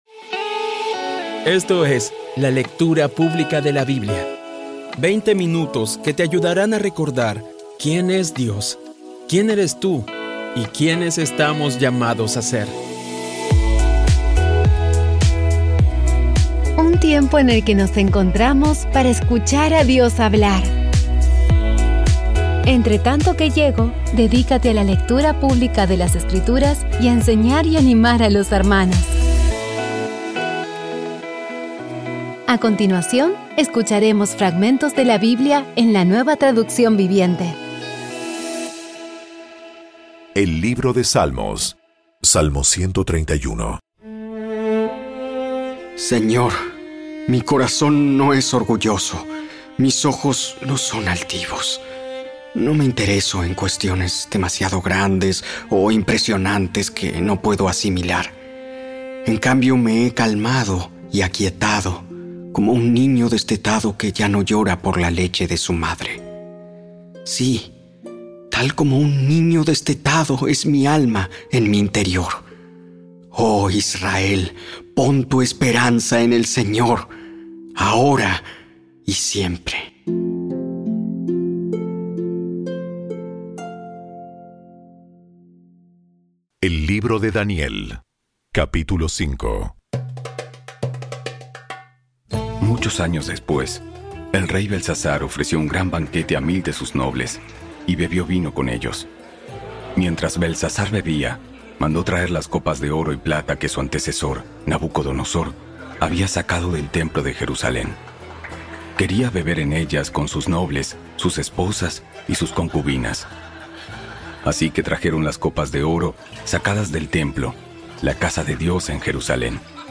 Audio Biblia Dramatizada Episodio 329
Poco a poco y con las maravillosas voces actuadas de los protagonistas vas degustando las palabras de esa guía que Dios nos dio.